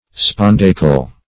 Spondaic \Spon*da"ic\ (sp[o^]n*d[=a]"[i^]k), Spondaical
\Spon*da"ic*al\ (-[i^]*kal), a. [L. spondaicus, spondiacus, Gr.